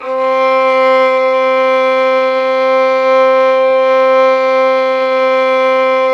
Index of /90_sSampleCDs/Roland L-CD702/VOL-1/STR_Violin 4 nv/STR_Vln4 _ marc
STR VLN BO03.wav